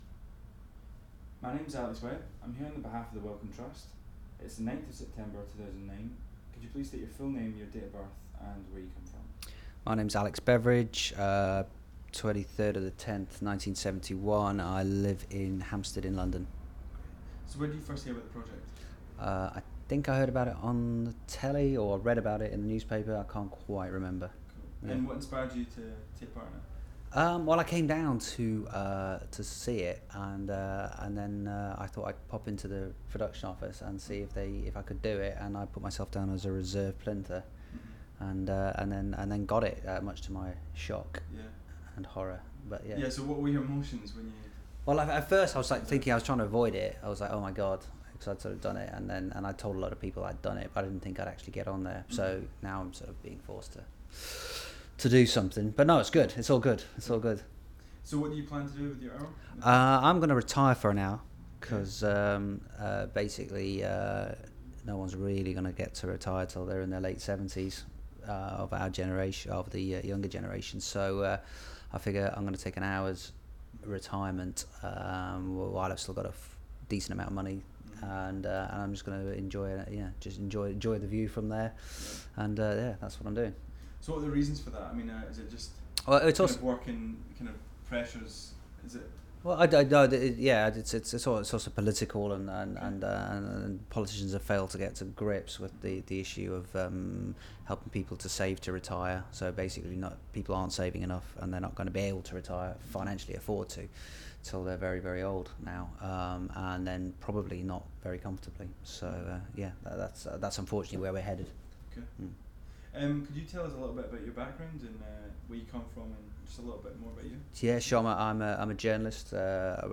Audio file duration: 00:08:40 Format of original recording: wav 44.1 khz 16 bit ZOOM digital recorder.
These recordings are part of the One & Other interview series that has been licensed by the Wellcome Trust for public use under Creative Commons Attribution-non commercial-Share Alike 3.00 UK.